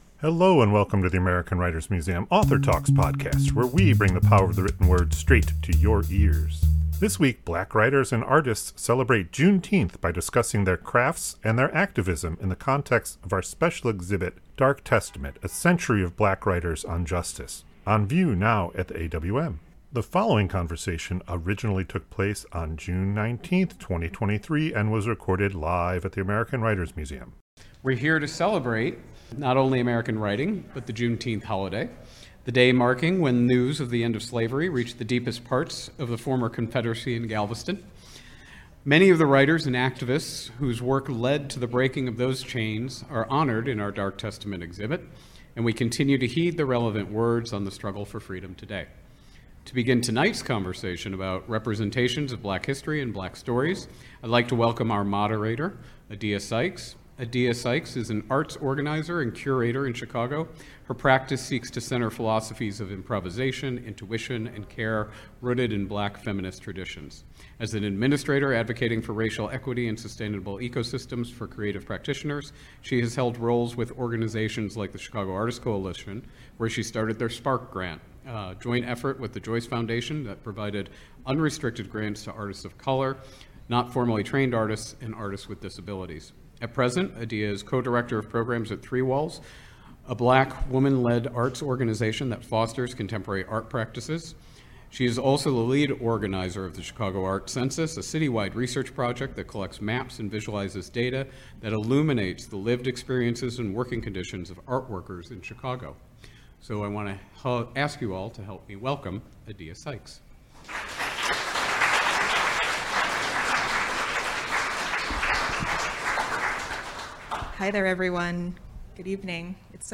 Black authors and artists celebrate Juneteenth and discuss their work and our exhibit Dark Testament: A Century of Black Writers on Justice.